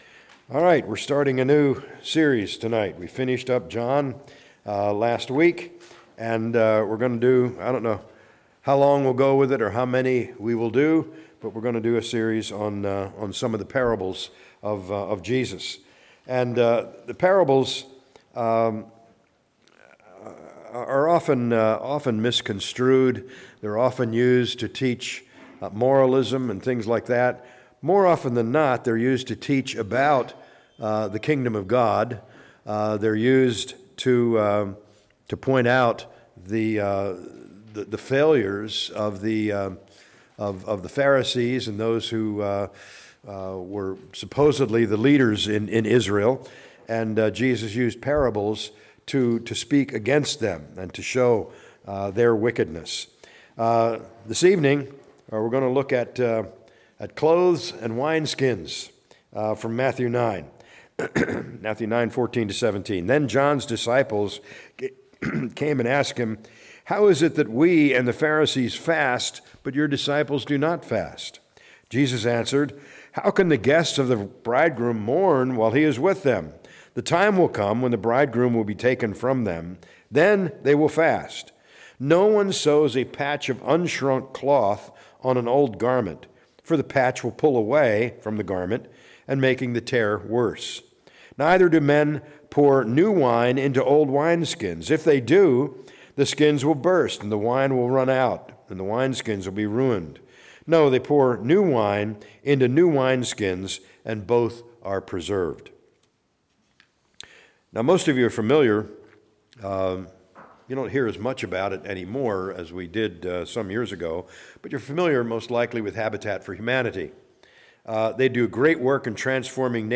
A message from the series "Parables of Jesus."